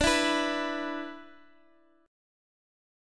E5/D chord
E-5th-D-x,x,x,7,5,7.m4a